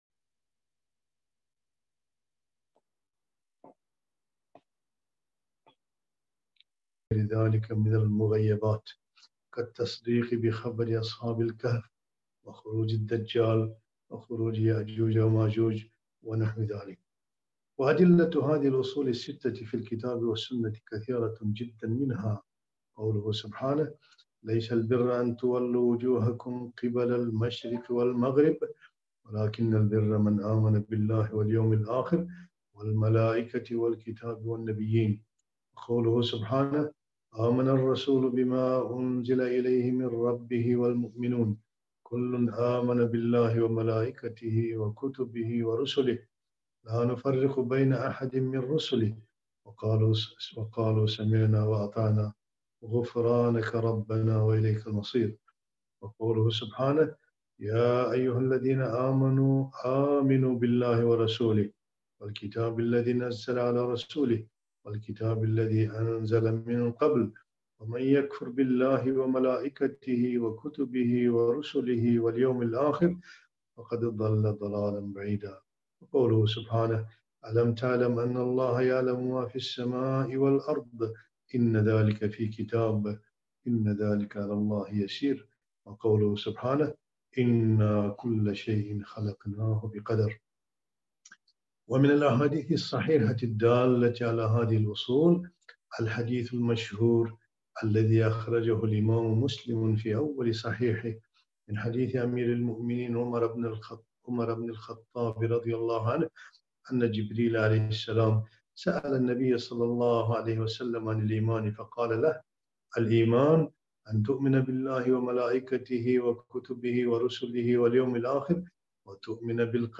محاضرة - مالا يسع المرأة المسلمة جهله في العقيدة - الجزء الأول